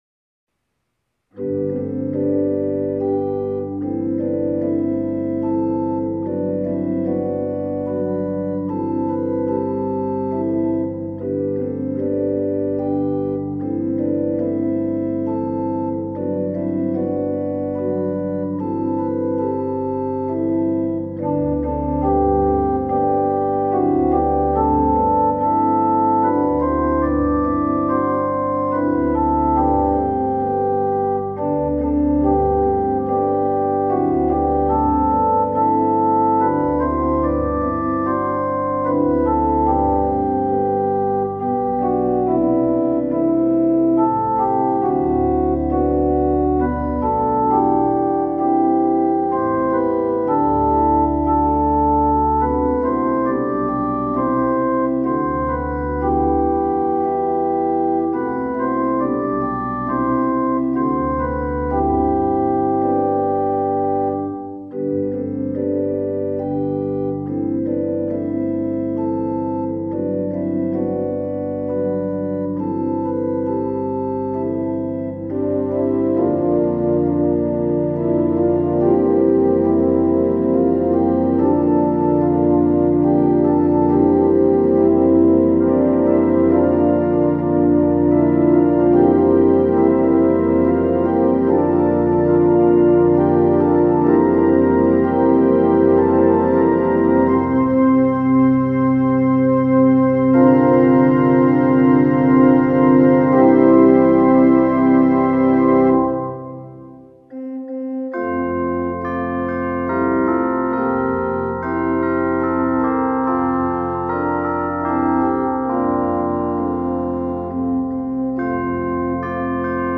Infant Holy, Infant Lowly is a beautiful Christmas carol from Poland. The tune traditionally sung to this poem compliments the message beautifully, with the verse rising to a gentle climax followed by an echoed retrospective.
I’ve come to associate chimes with Christmastime, and I’ve used them in this arrangement to provide a descant on the melody.